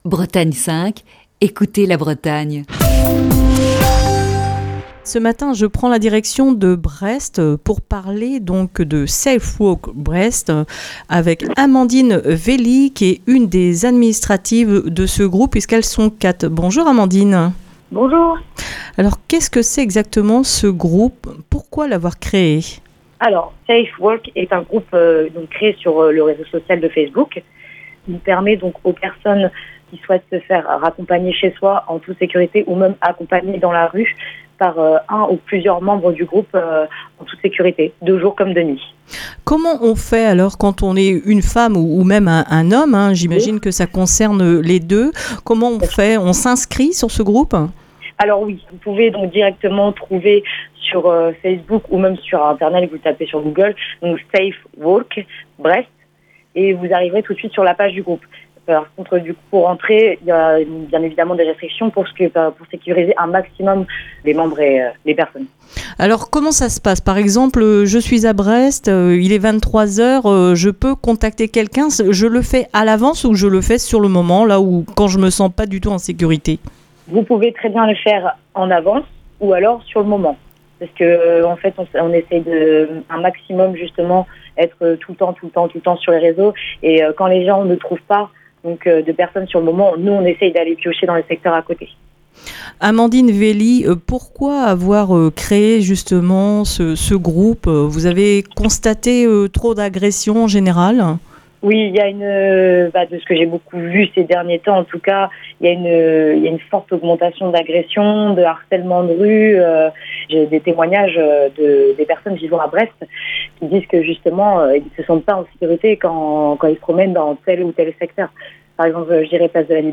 Ce lundi dans le coup de fil du matin